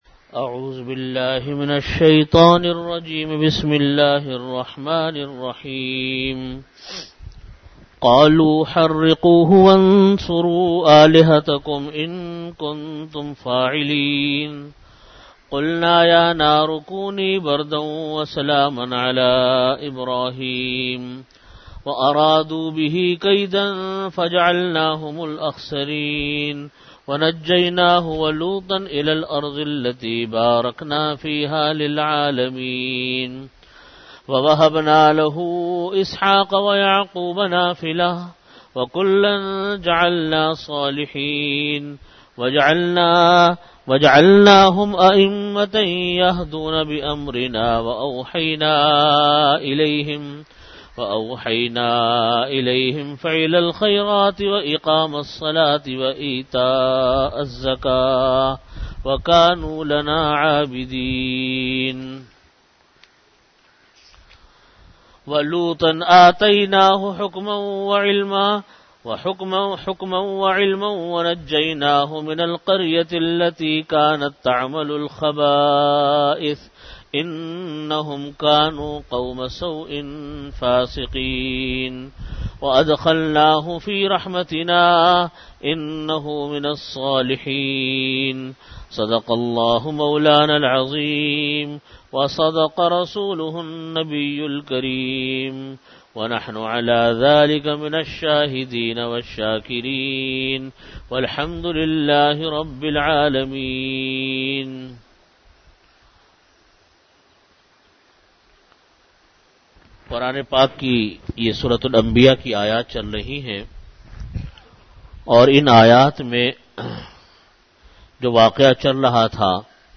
Audio Category: Bayanat
Time: After Asar Prayer Venue: Jamia Masjid Bait-ul-Mukkaram, Karachi